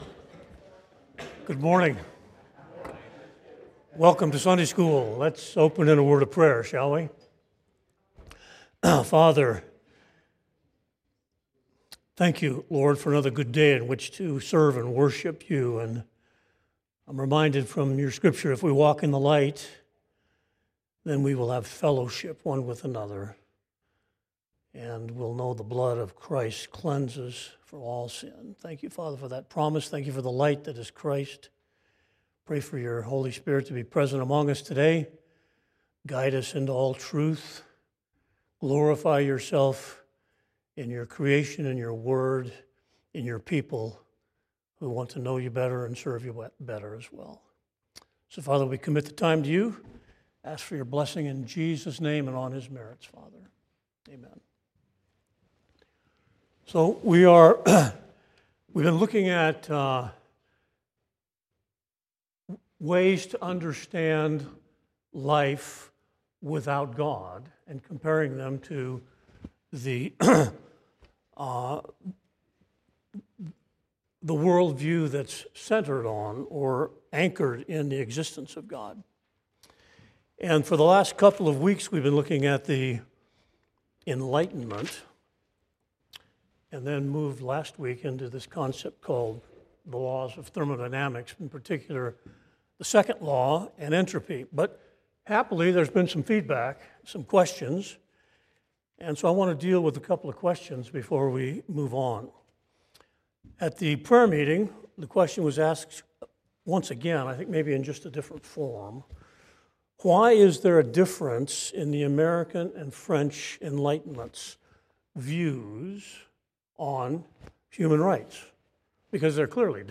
Lesson 8 (Sunday School)